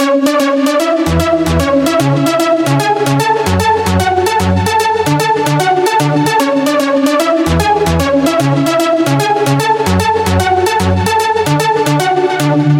Tag: 150 bpm Hardstyle Loops Synth Loops 2.16 MB wav Key : A